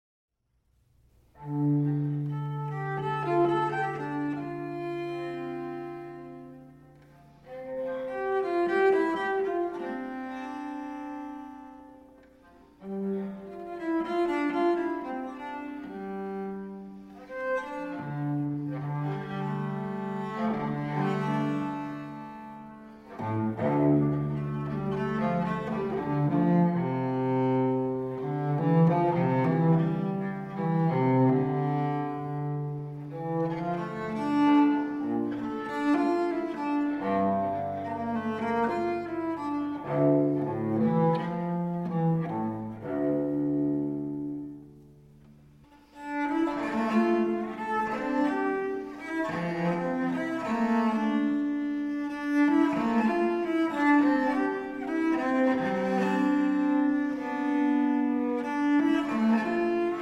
A Renaissance Band
vielle, viols